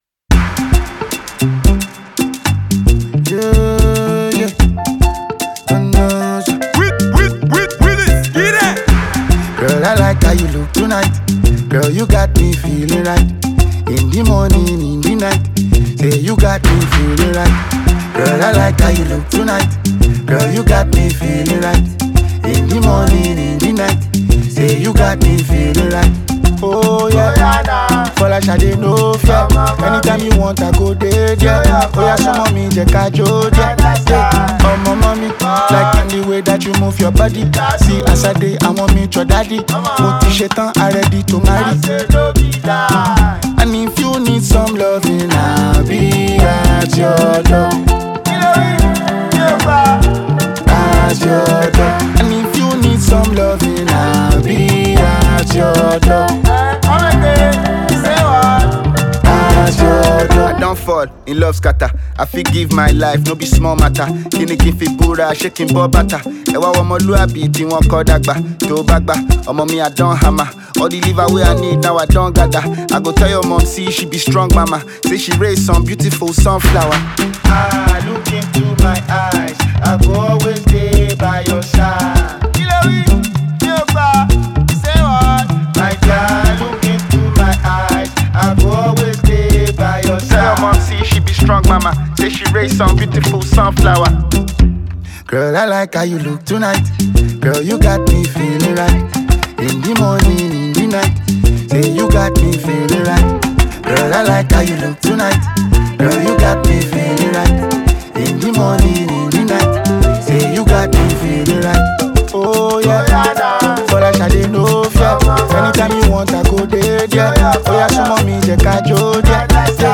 This track is a love song with a melodious chorus.